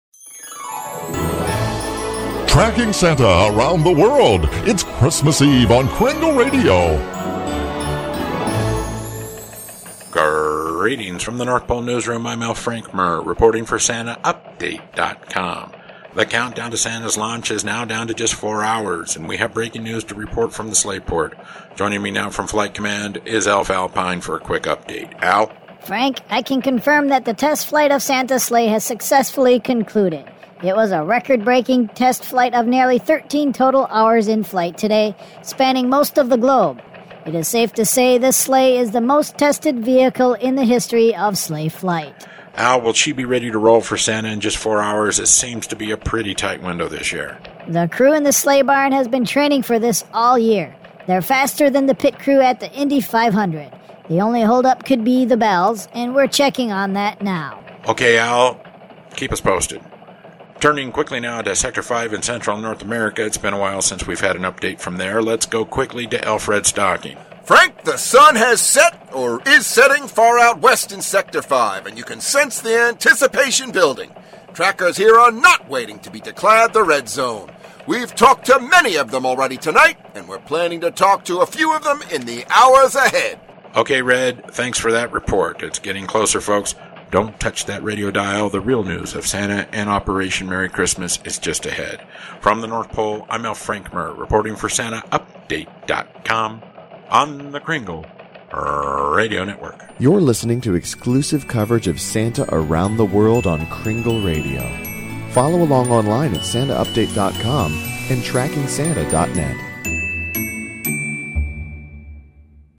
NORTH POLE RADIO NEWS